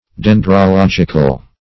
Meaning of dendrological. dendrological synonyms, pronunciation, spelling and more from Free Dictionary.